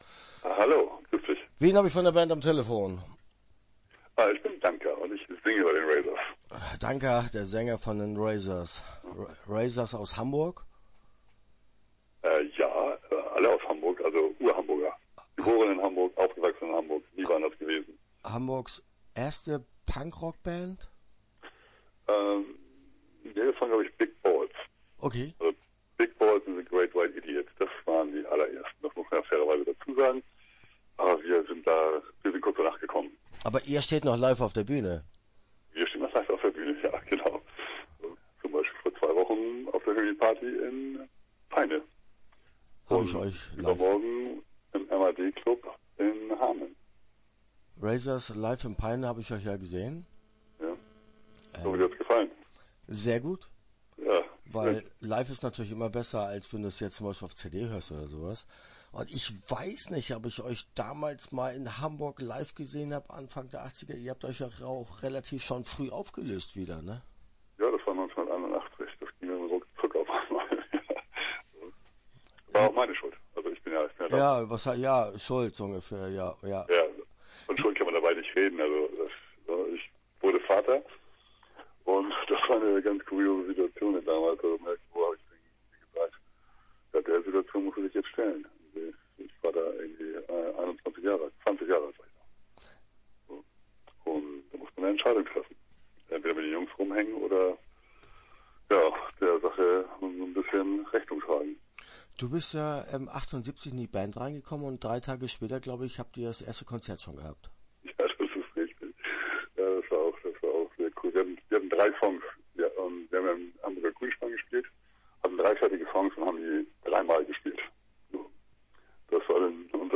Start » Interviews » Razors